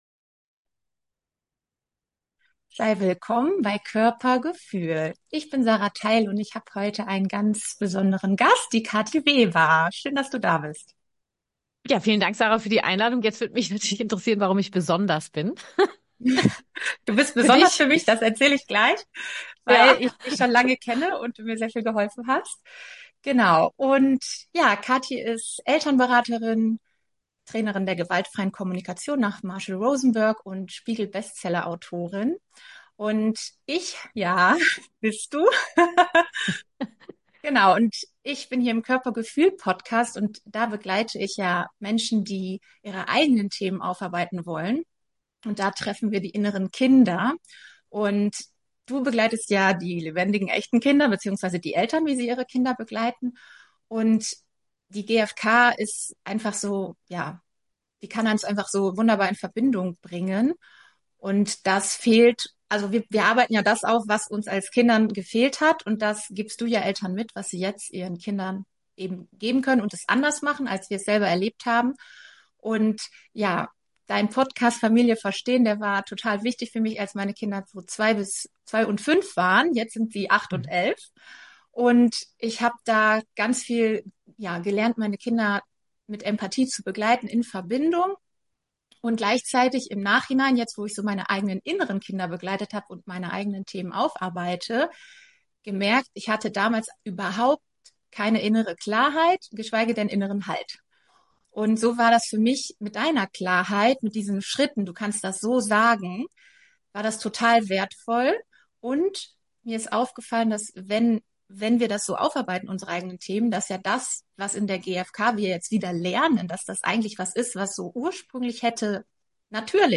Ein Gespräch über Mut, Verantwortung und die Kraft, neue Wege zu gehen – für uns und unsere Kinder.